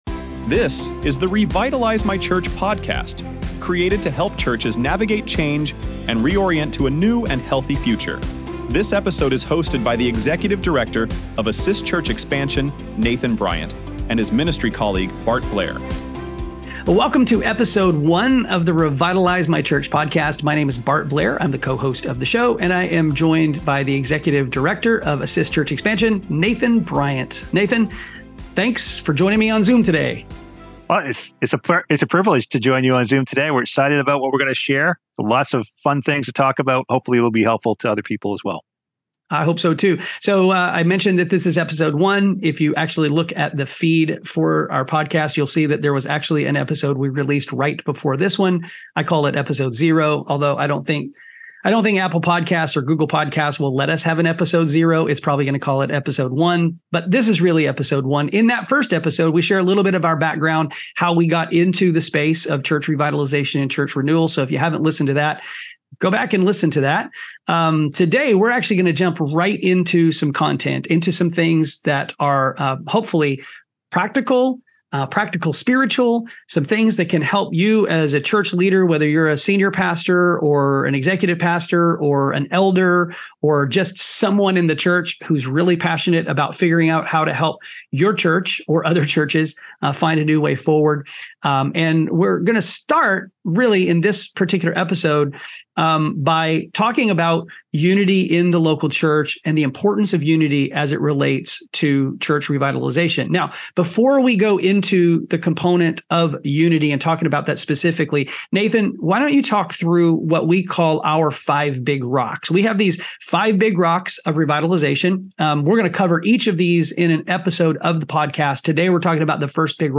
Drawing from biblical passages such as Psalm 133, the hosts emphasize that unity among believers leads to a spiritual anointing that enables the church to move forward in its mission. The conversation highlights the challenges that pastors and church leaders face when att